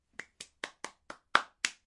无人驾驶飞机 CoJMC无人机实验室
描述：这是无人驾驶飞机在内布拉斯加大学林肯分校新闻与大众传播学院的无人机实验室运行的声音。
Tag: 无人驾驶飞机 飞行 无人驾驶飞机 螺旋桨 无人机aircra FT